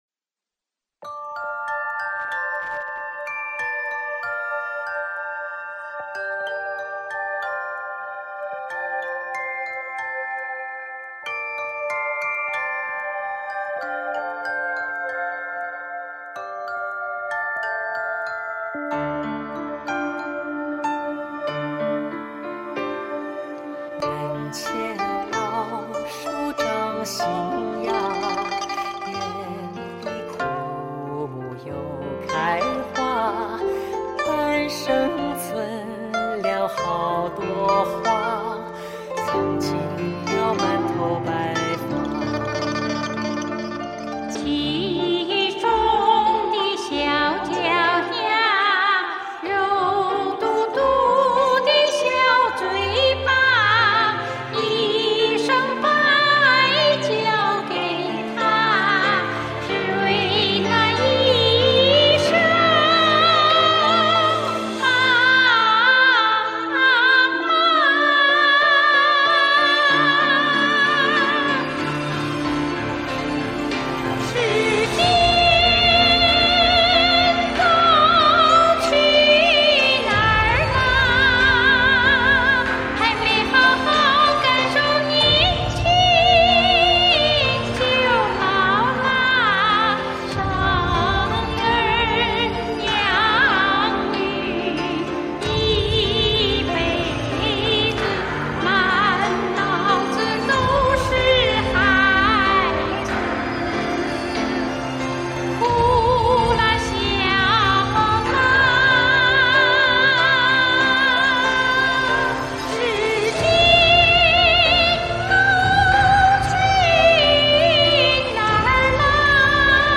京韵味道更是感染人！